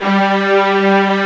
Index of /90_sSampleCDs/Roland L-CD702/VOL-1/CMB_Combos 2/CMB_Bryt Strings